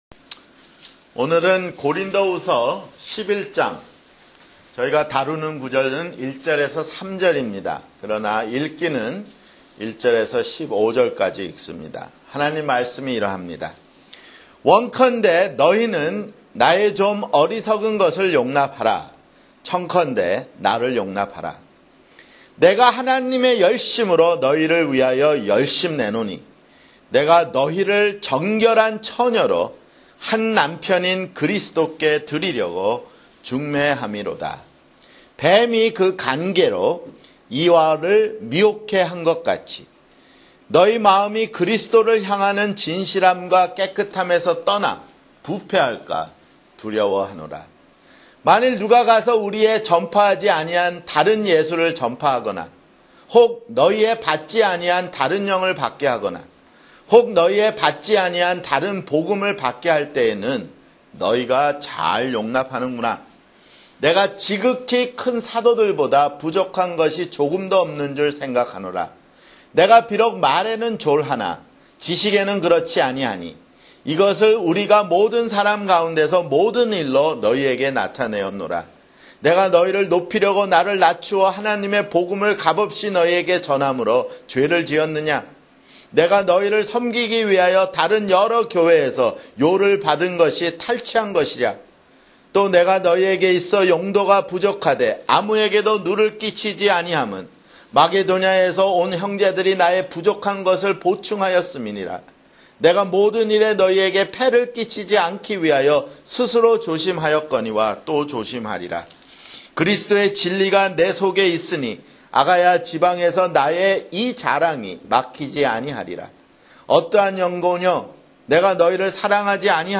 [주일설교] 고린도후서 (50)